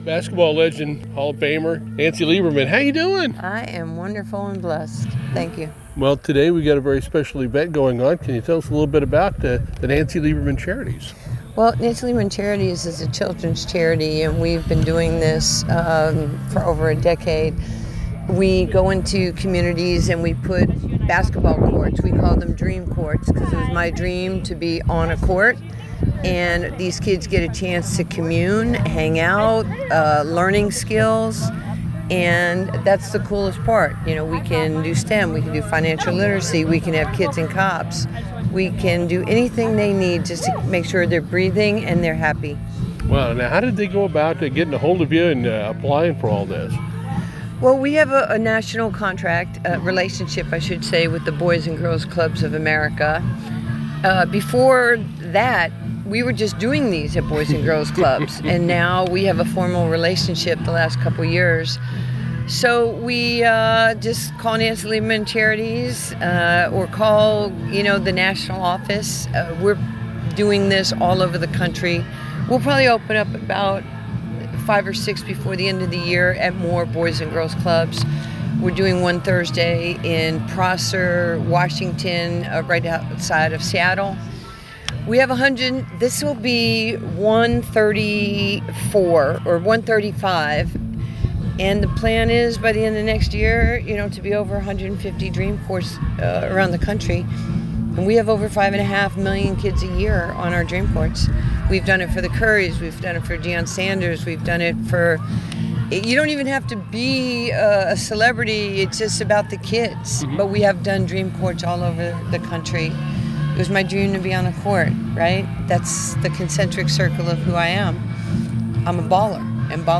ONE ON ONE WITH NANCY LIEBERMAN